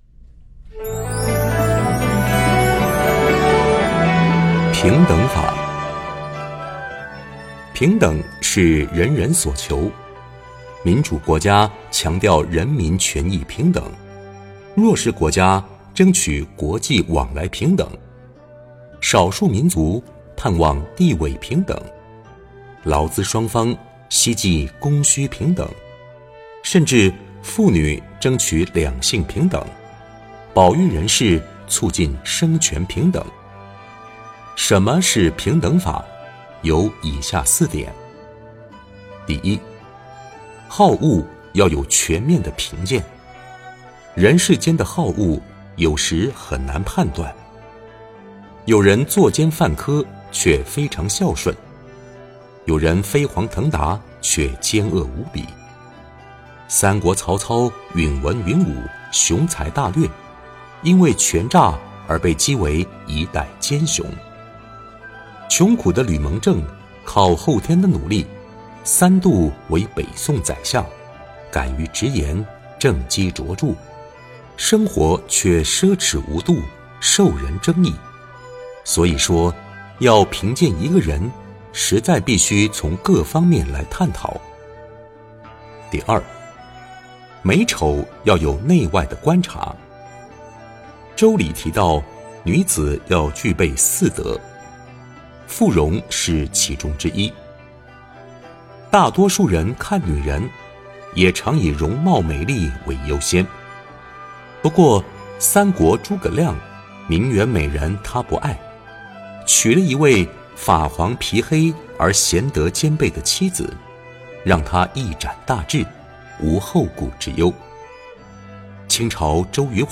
平等法 诵经 09. 平等法--佚名 点我： 标签: 佛音 诵经 佛教音乐 返回列表 上一篇： 圣迹的因缘 下一篇： 10.